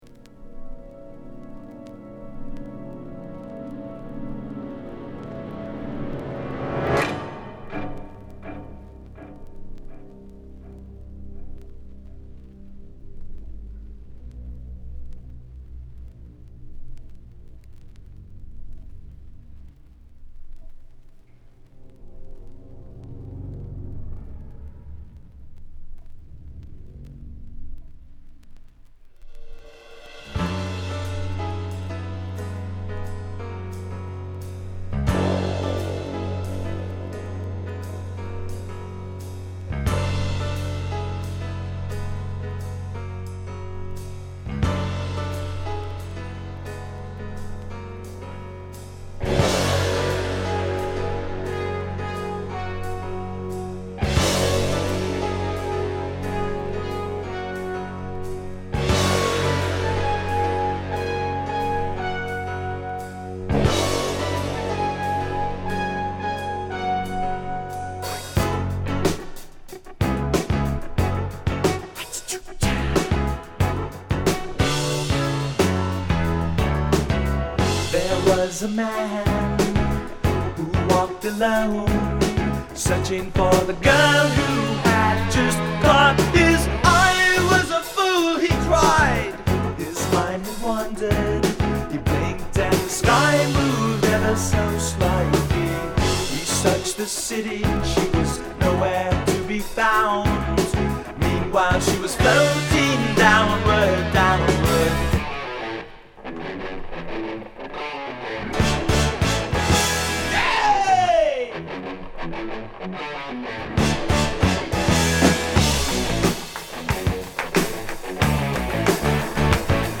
プログレ感も交えたロック〜ハードロックを収録。